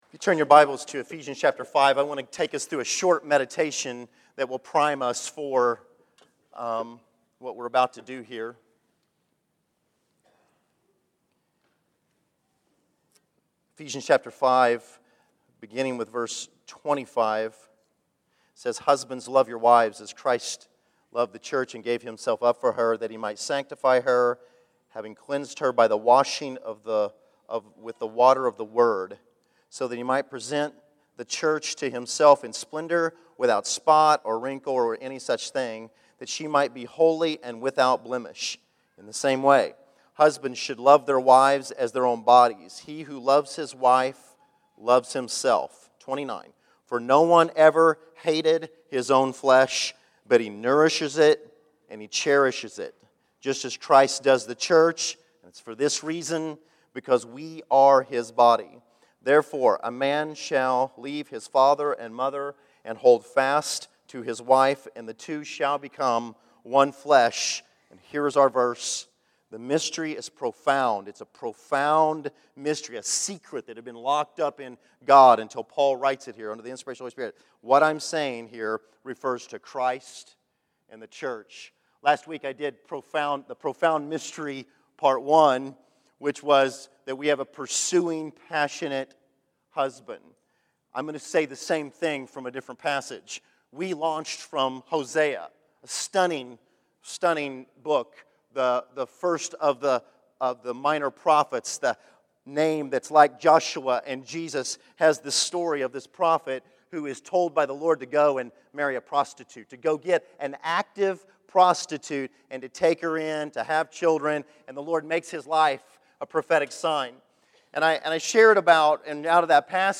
The Profound Mystery, Part 2 May 05, 2013 Category: Sermons | Back to the Resource Library Video Audio The Heavenly Husband’s preparing of a wife.